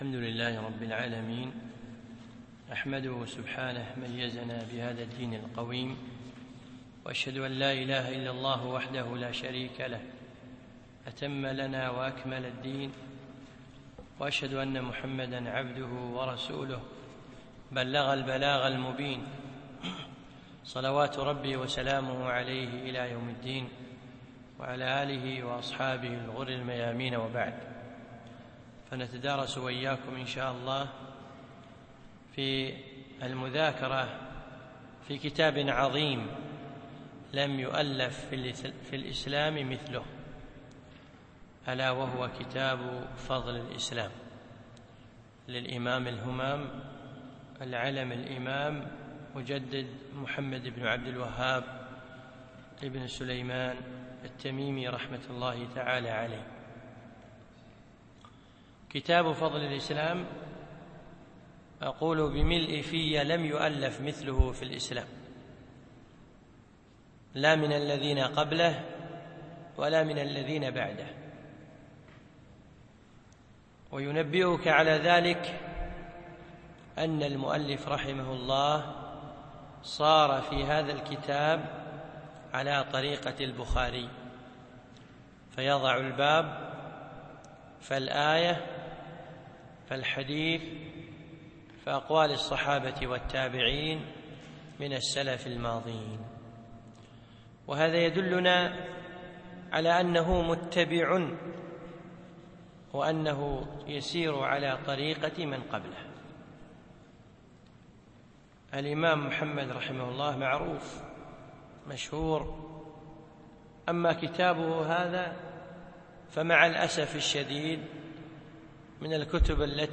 من دروس الشيخ حفظه الله في دولة الإمارات